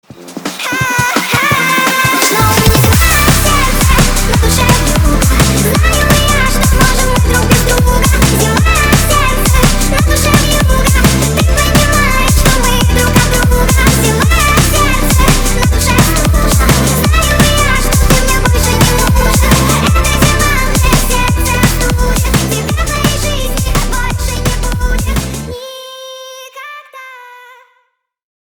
bass boosted
хардстайл